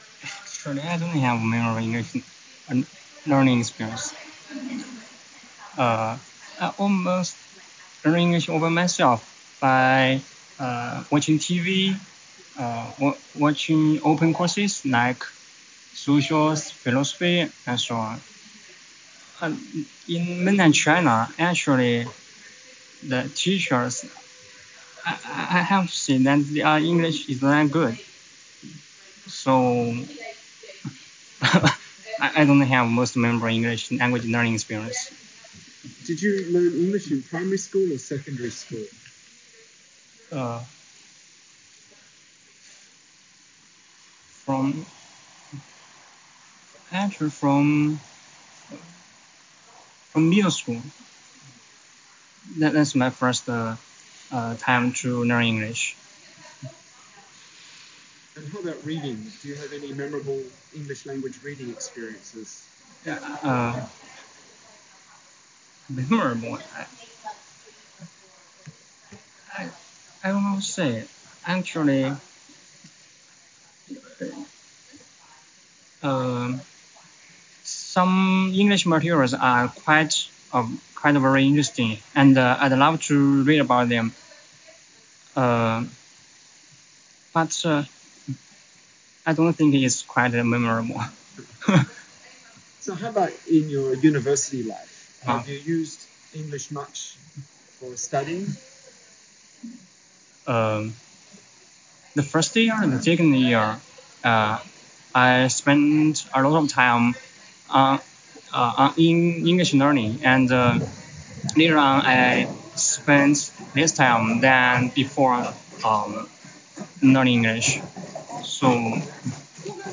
A postgraduate student praises his middle school teachers and explains he continues to learn English through online open courses and Wikipedia and that all master’s classes are in English.